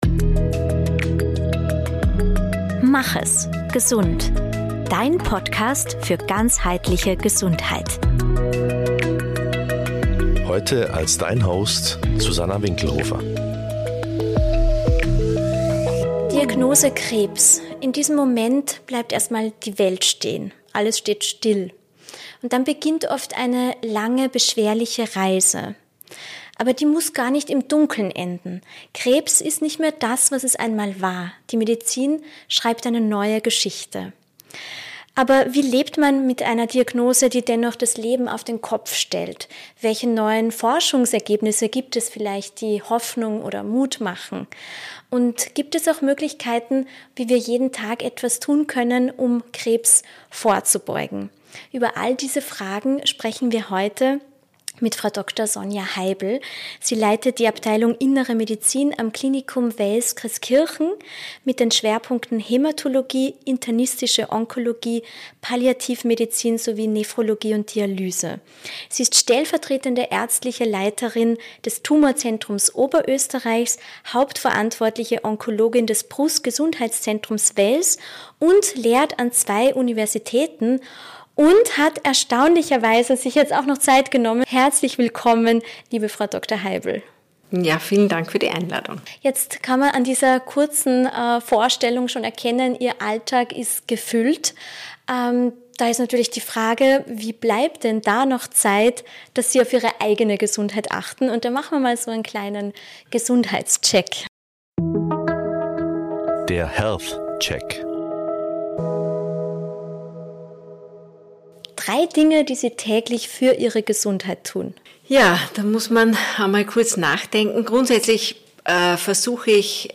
Krebs ist nicht mehr das, was es einmal war. Ein Gespräch, das Mut macht.